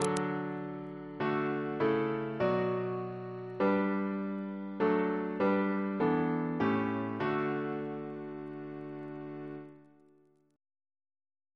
Single chant in D Composer: Richard Haking (1830-1894) Reference psalters: OCB: 69